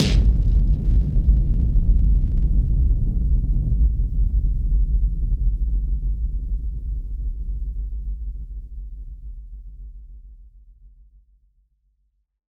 BF_HitSplosionB-04.wav